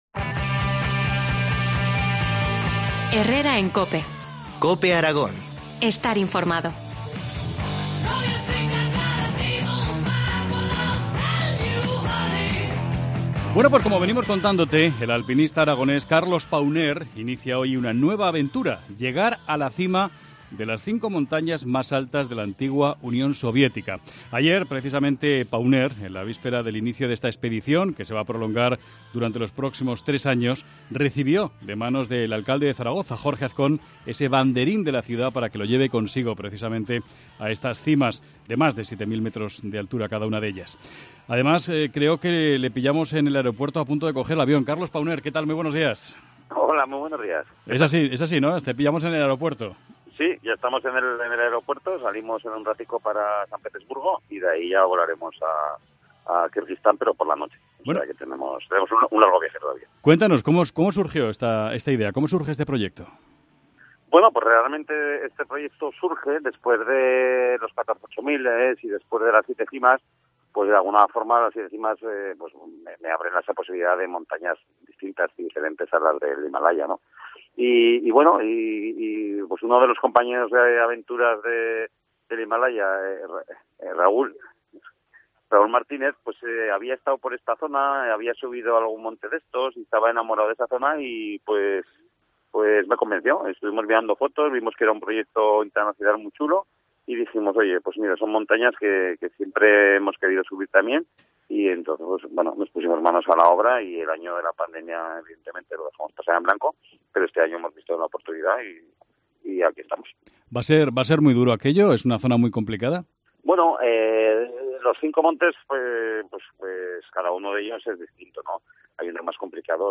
Entrevista al alpinista aragonés, Carlos Pauner.